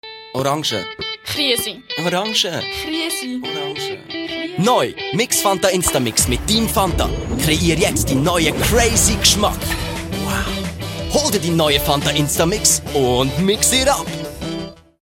Werbung Schweizerdeutsch (AG)
Schauspieler mit breitem Einsatzspektrum.